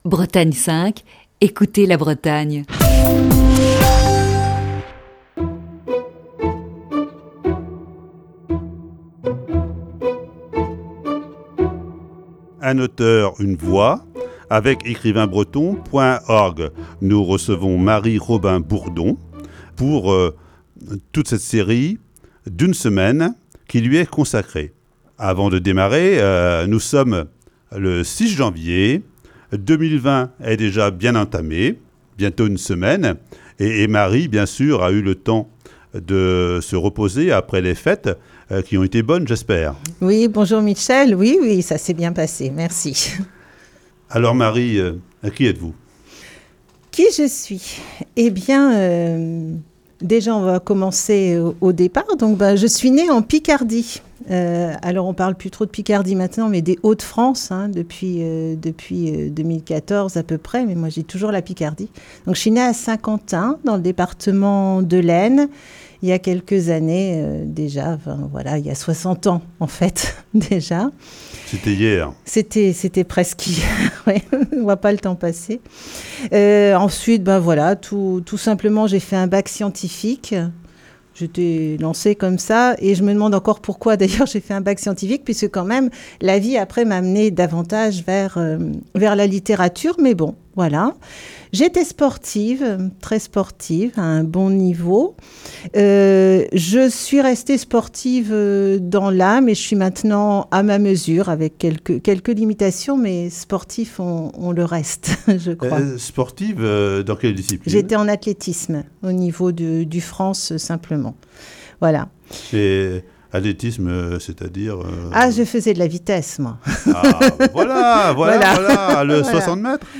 Voici, ce lundi, la première partie de cet entretien.